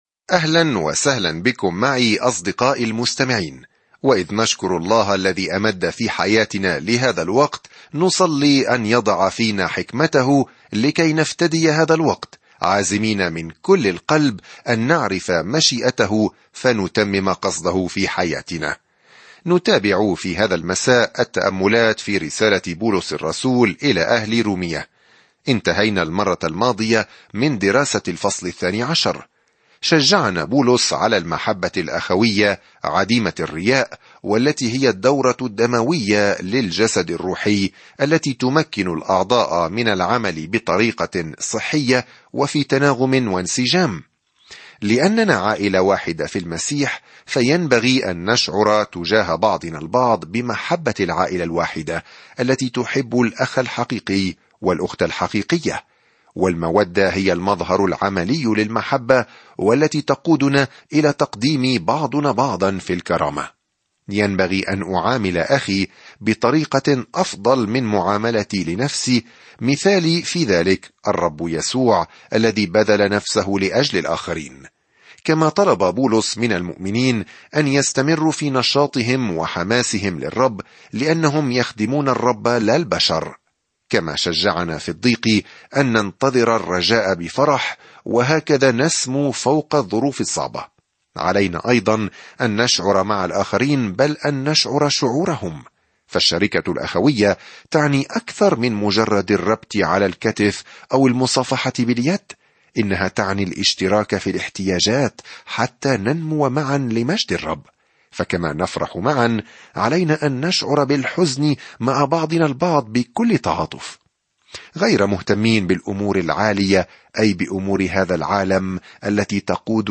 الكلمة رُومِيَةَ 13 يوم 28 ابدأ هذه الخطة يوم 30 عن هذه الخطة الرسالة إلى أهل رومية تجيب على السؤال: "ما هي البشارة؟" وكيف يمكن لأي شخص أن يؤمن، ويخلص، ويتحرر من الموت، وينمو في الإيمان. سافر يوميًا عبر رسالة رومية وأنت تستمع إلى الدراسة الصوتية وتقرأ آيات مختارة من كلمة الله.